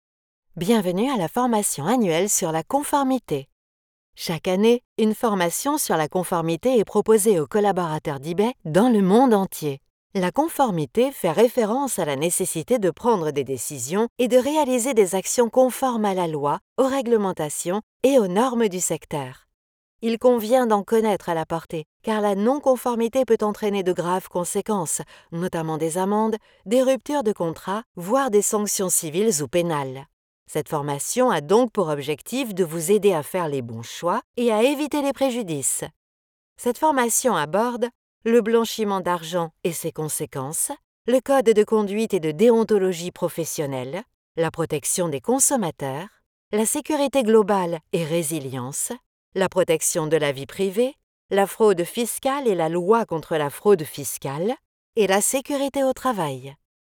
Female
Approachable, Confident, Corporate, Friendly, Reassuring, Versatile, Warm
Explainer-Medical.mp3
Microphone: Neumann TLM 103
Audio equipment: RME Fireface UC, separate Soundproof whisper room